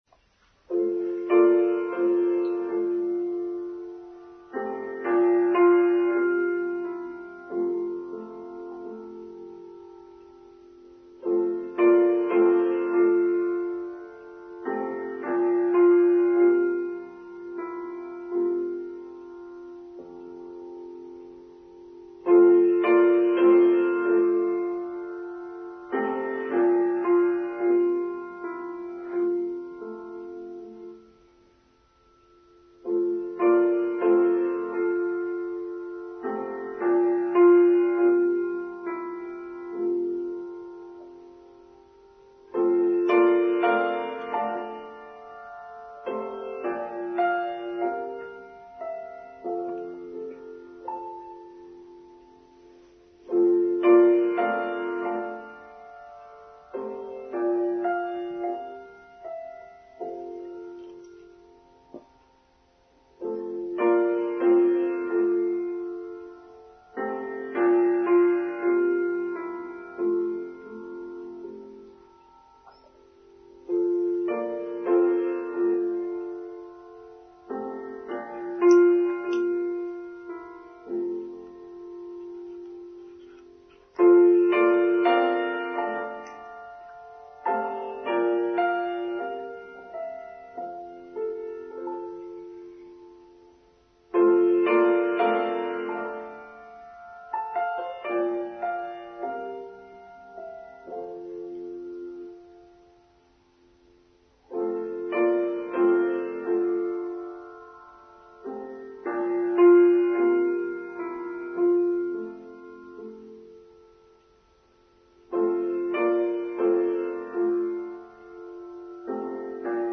The Mystery of Humankind: Online Service for Sunday 9th October 2022